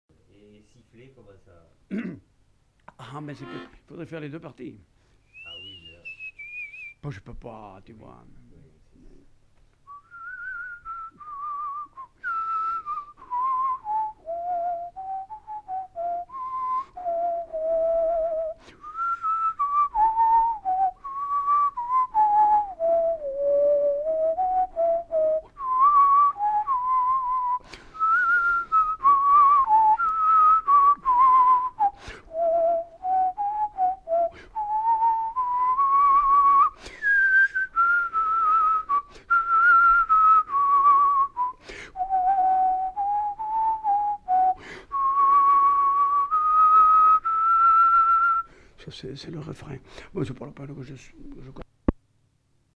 Valse (sifflé)
Aire culturelle : Haut-Agenais
Lieu : Lougratte
Genre : chant
Effectif : 1
Type de voix : voix d'homme
Production du son : sifflé
Danse : valse